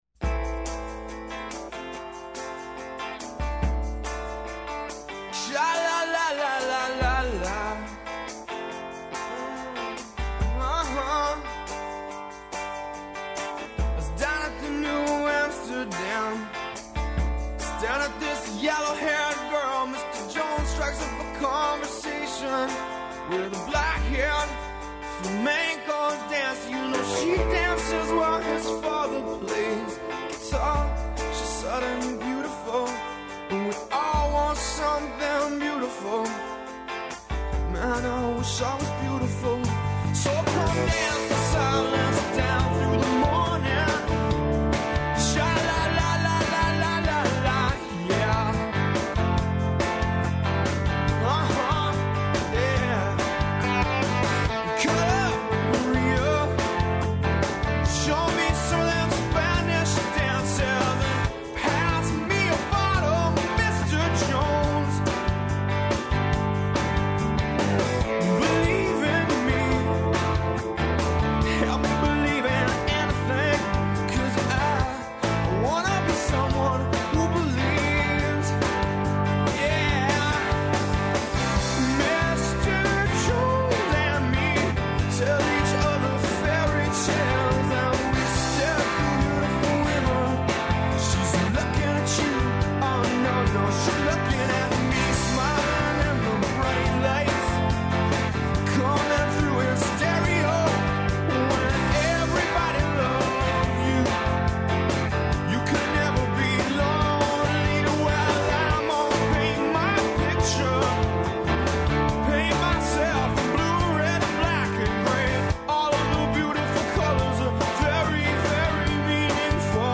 Доносимо извештај са конференције 'Одговорност академске заједнице’’ (11.12.ДОБ.) где се говорило о академској честитости али и обавезама факулета да на својим интернет презентацијама објављују ''мерила за утврђивање школарине’’ на шта их обавезују измене и допуне Закона о високом образовању.